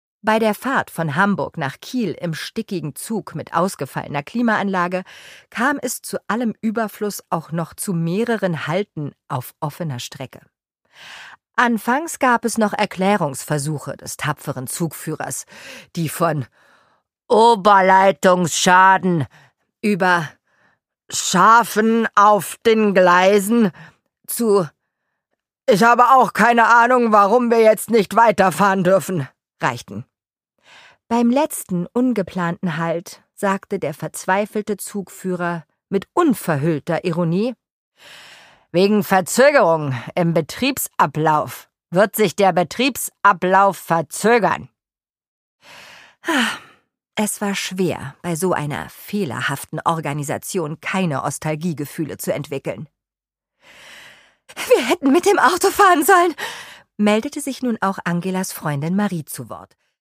Produkttyp: Hörbuch-Download
Gelesen von: Nana Spier
Denn ihre vielseitige Interpretationskunst und ihr Gespür für Witz und Timing kommen in dieser Reihe voll zum Tragen.